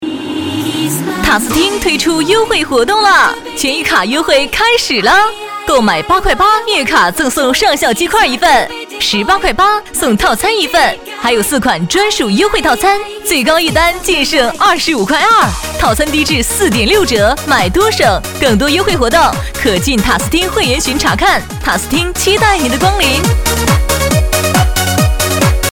促销女 | 声腾文化传媒
【促销】活动女117.mp3